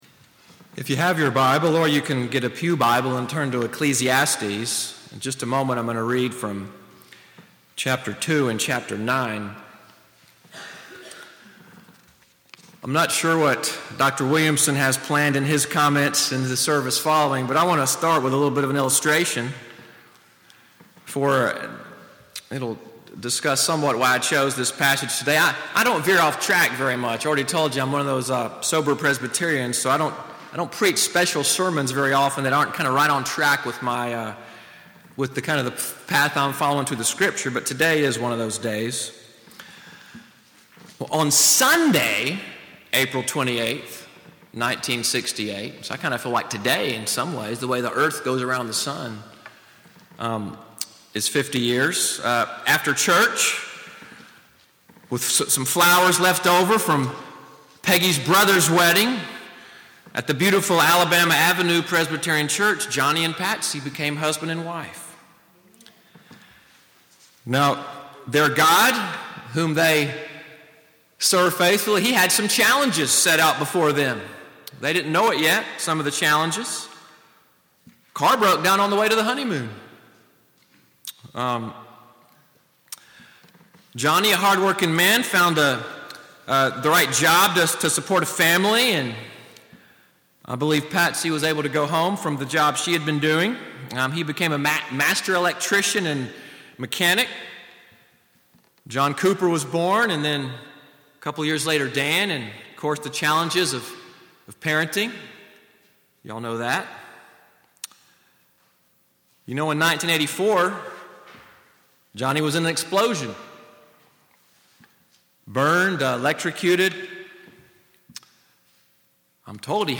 MORNING WORSHIP at NCPC-Selma, audio from the special sermon, “Experiencing His Favor,” as we celebrated a 50th wedding anniversary.